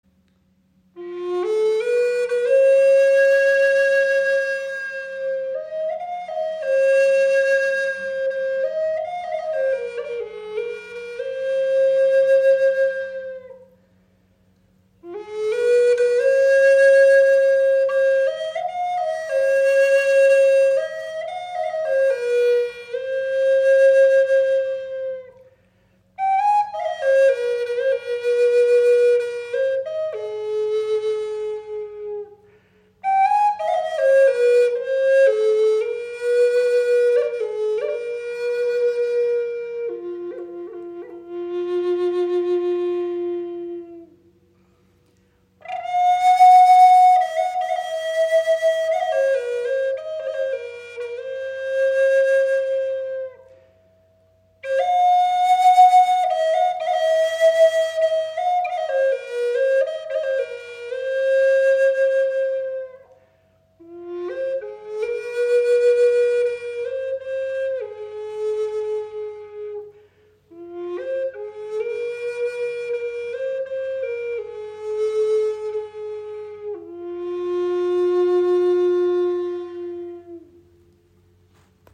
Gebetsflöte in F
Wundervolle Flöte aus brasilianischem Imbuiaholz
• Icon 53 cm lang, 6 Grifflöcher
Sie schenkt Dir ein wundervolles Fibrato, kann als Soloinstrument gespielt werden oder als weiche Untermahlung Deiner Musik.